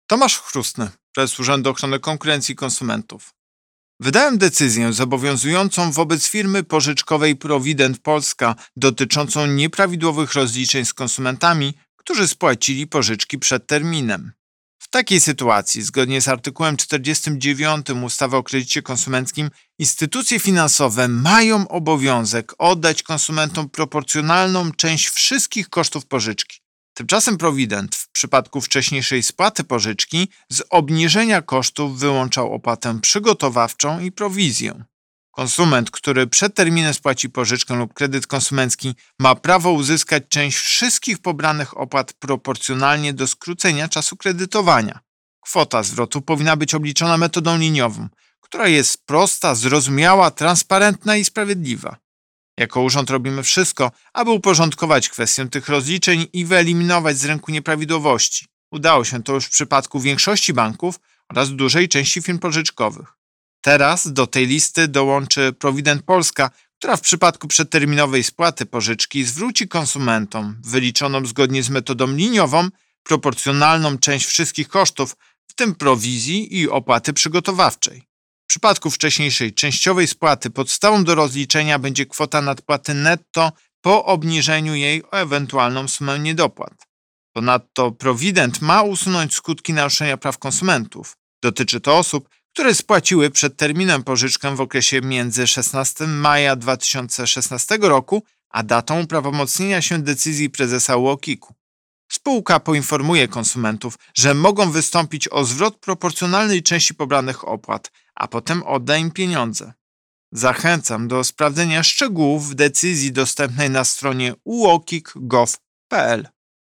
Wypowiedź Prezesa UOKiK Tomasza Chróstnego z 3 sierpnia 2021 r..mp3